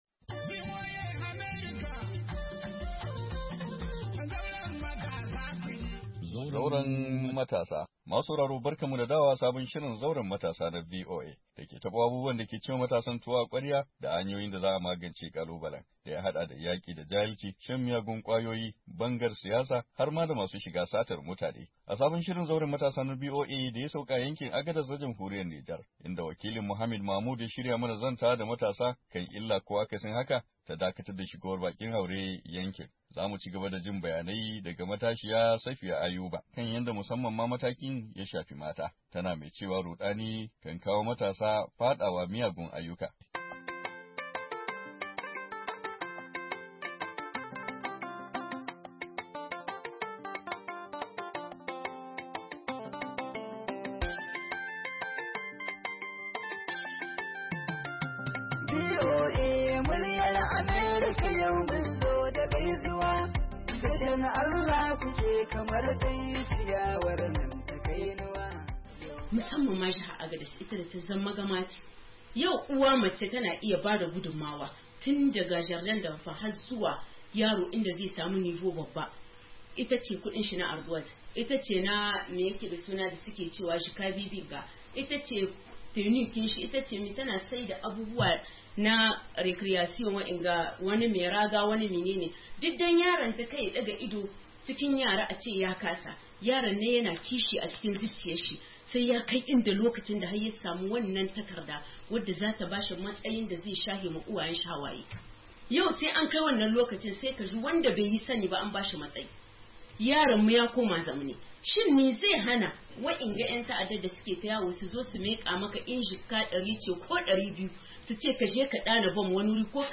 Kashi na biyu na kuma karshe na shirin da a ka dauka a yankin Agadas na jamhuriyar Nijar inda hana shigar bakin haure yankin ya taba hanyoyin samun kudin shiga ga matasa.